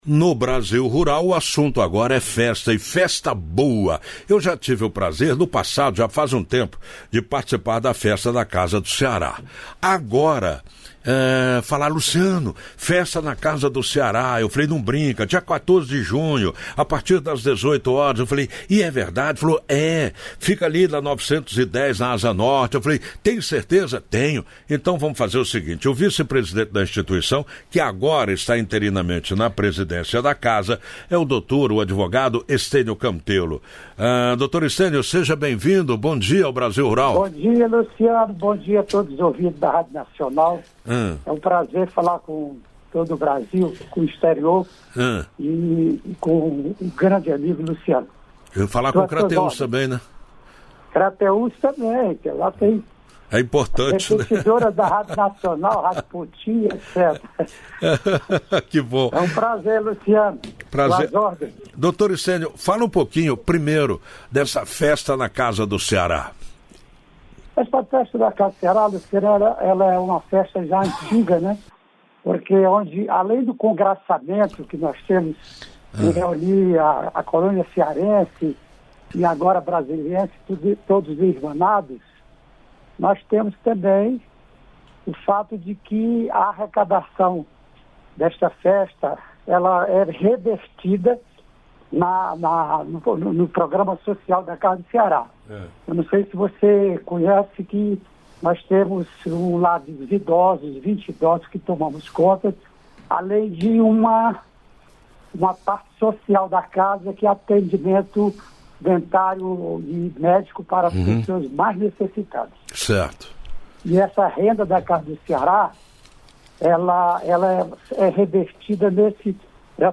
Conversamos com